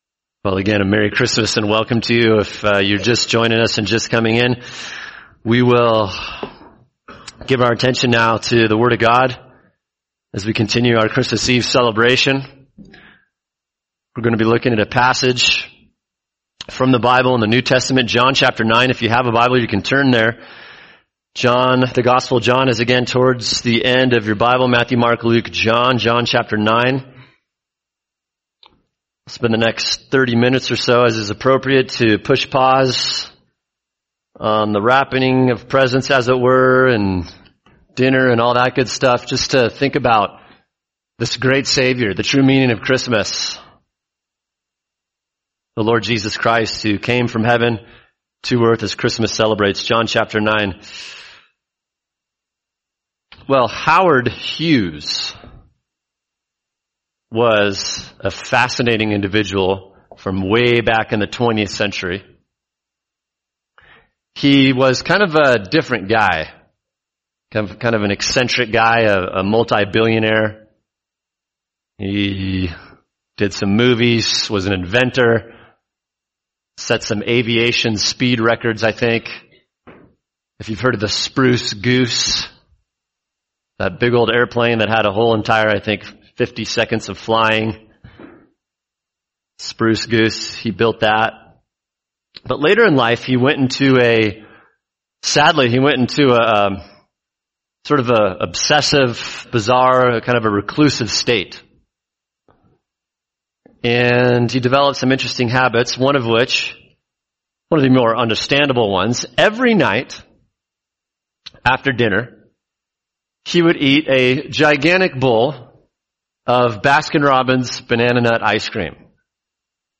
[sermon] John 9 – The Savior Opens Blind Eyes | Cornerstone Church - Jackson Hole